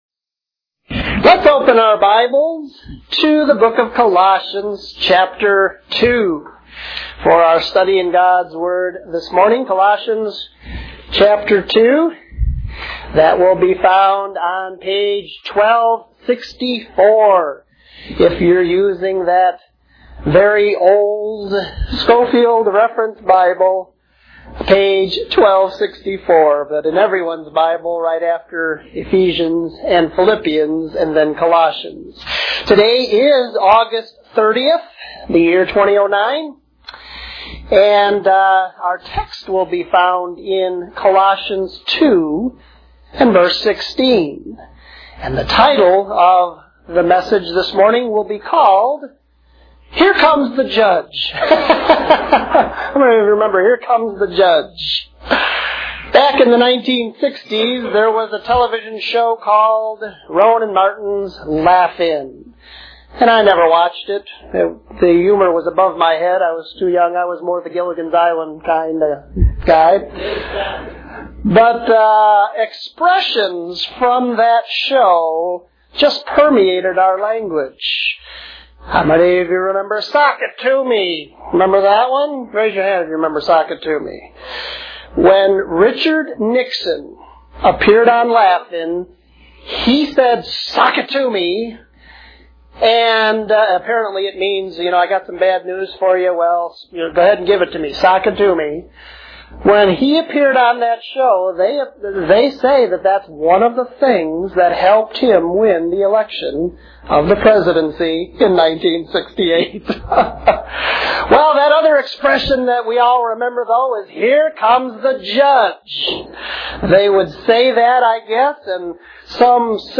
Lesson 29: Colossians 2:16-17